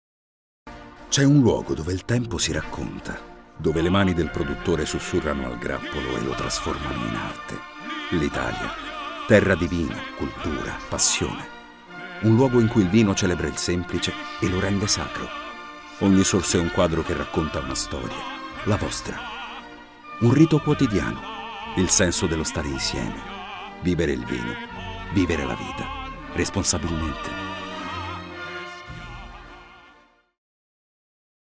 Lo spot radio